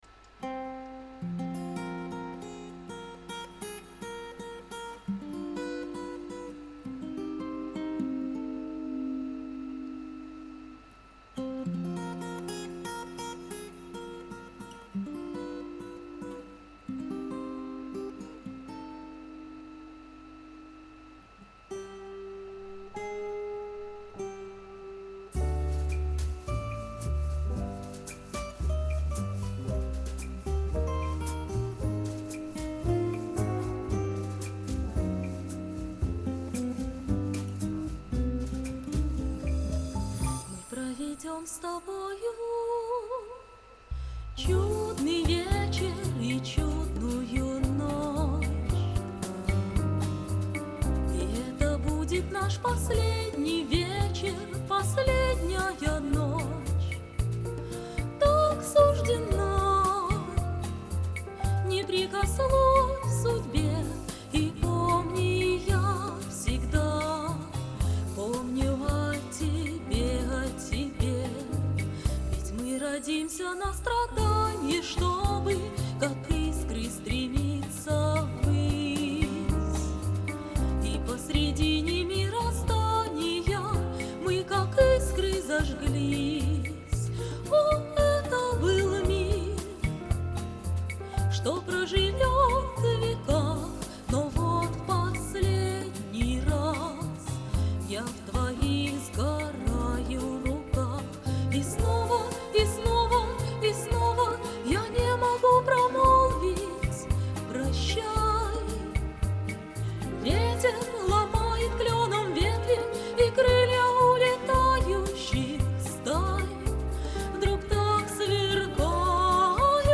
Девочки,обе молодцы,прекрасные голоса и чудесные исполнения!